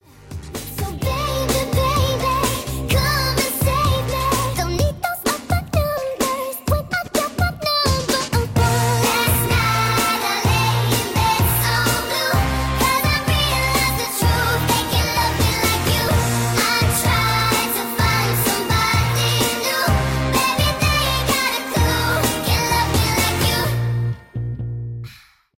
Phone hack water detected 🫧🫧🫧 sound effects free download